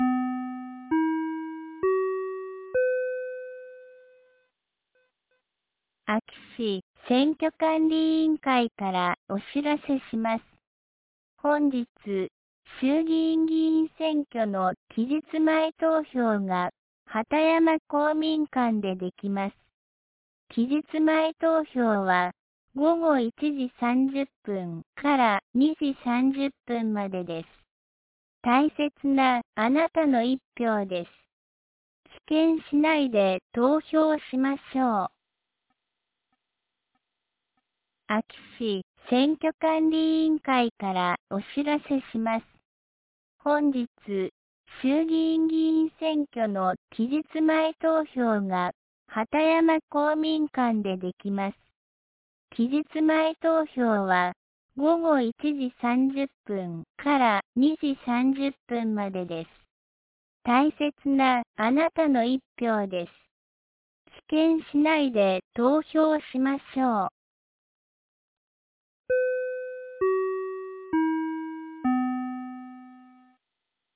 2026年02月05日 09時05分に、安芸市より畑山へ放送がありました。